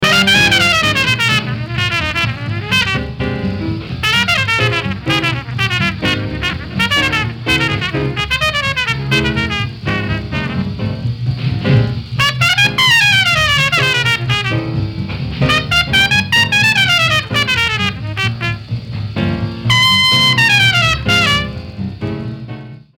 A bebop classic!